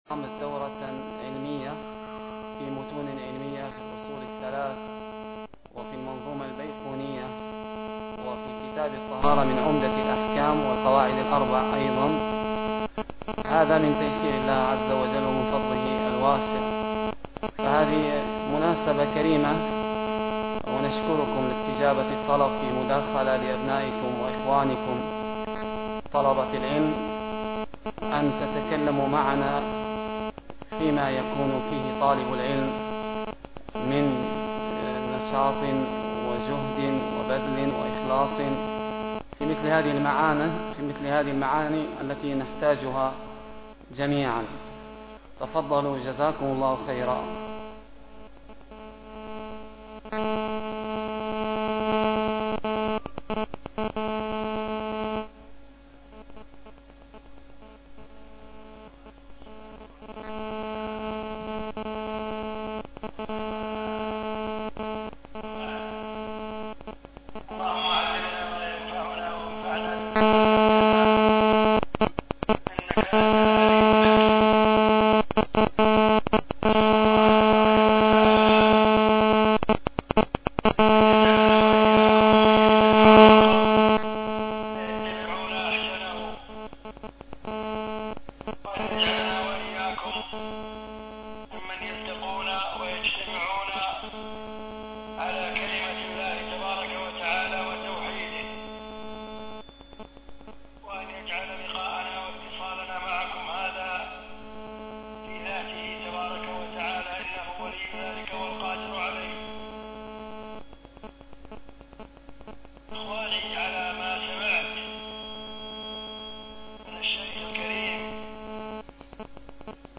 كلمة عبر الهاتف لأبنائه طلبة العلم في ليبيا - دورة ابن القيم